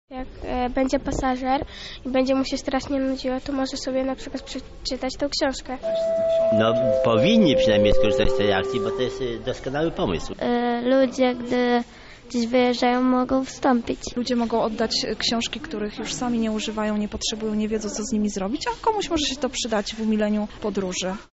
Na półce stanęły książki zarówno dla dzieci, jak i dorosłych. Pasażerowie zgodnie przyznają, że z wypożyczalni będą korzystać przy każdej okazji.